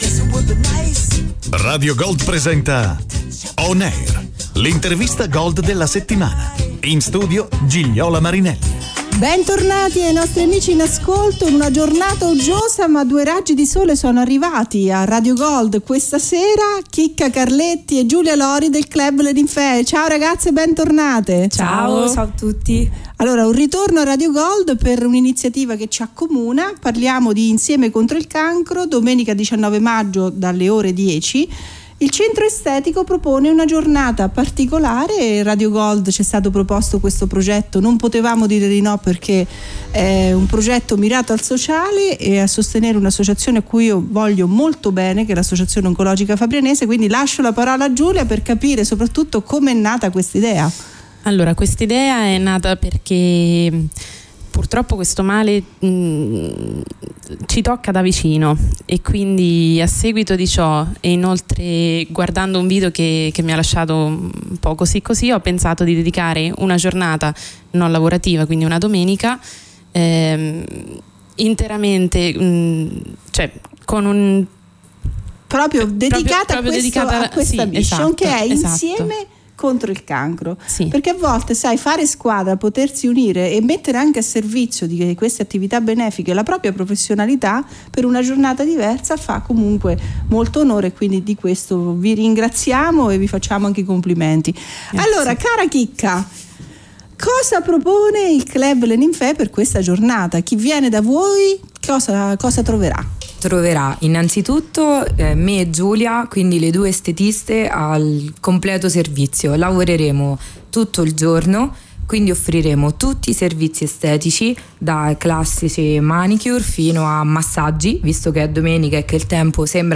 Ascolta l’intervista Gold della settimana